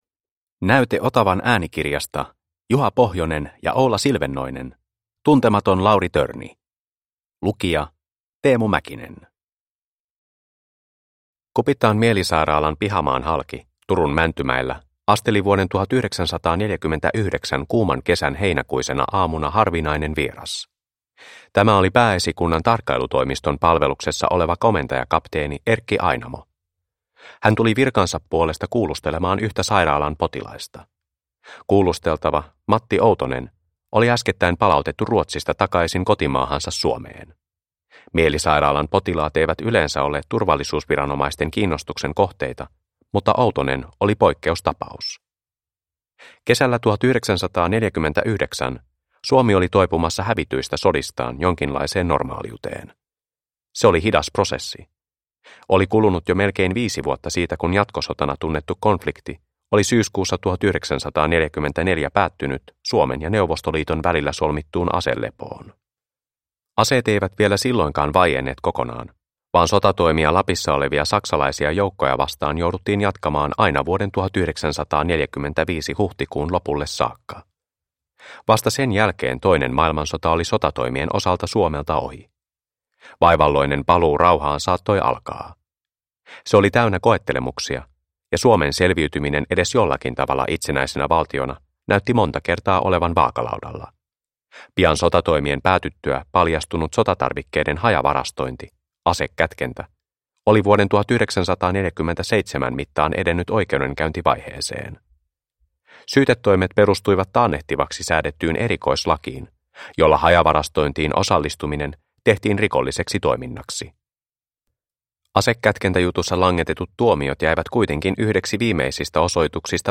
Tuntematon Lauri Törni – Ljudbok – Laddas ner